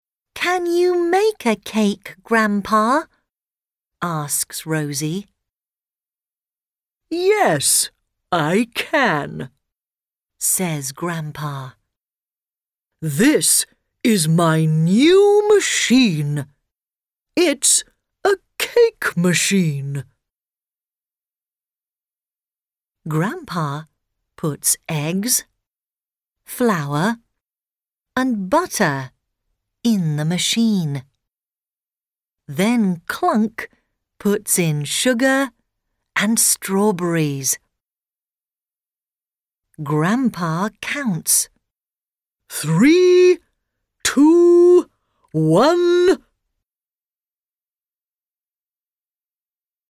Track 2 The Cake Machine British English.mp3